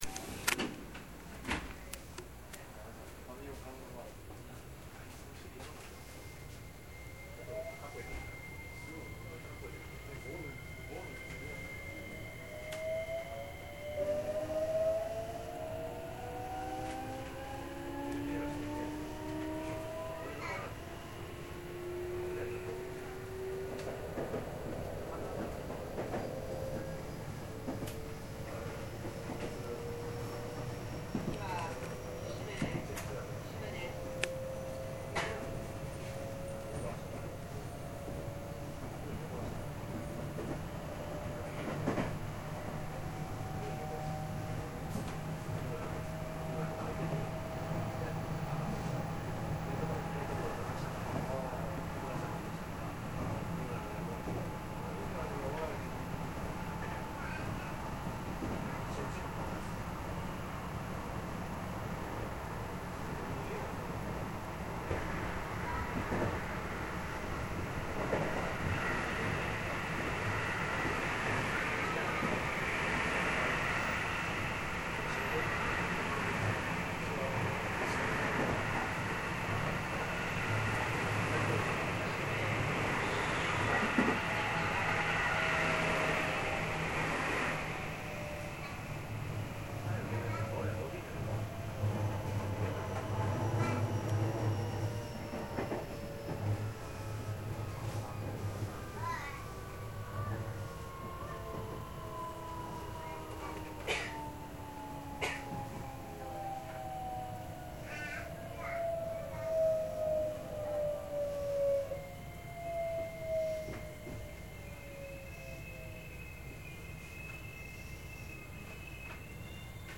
321系走行音
@ 普通 東洋 茨木〜千里丘 再生
321系の走行音としての特徴は従来の207系や223系とは違った走行音を発し、高速域での騒音もとても静かになったことです。
M車（クモハ３２１、モハ３２１）、M'車(クモハ３２０、モハ３２０)で音が違うのです。